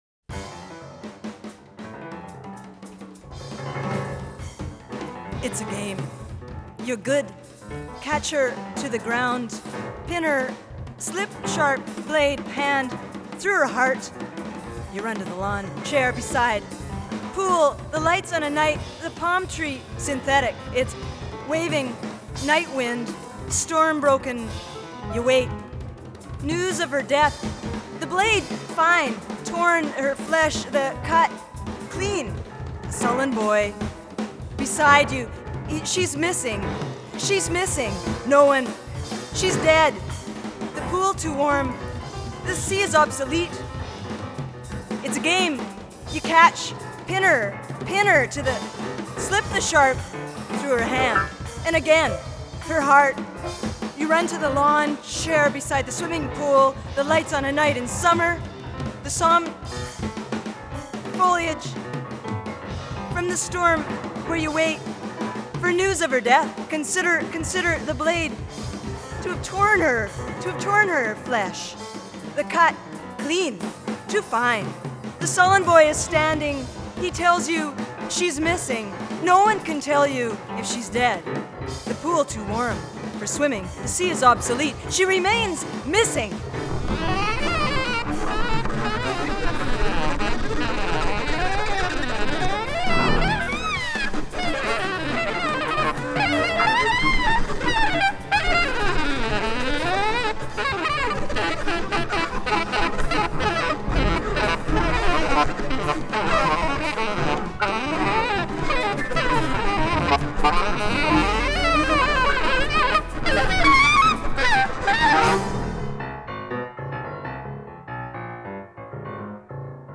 Recorded at CBC Studio 11 in Winnipeg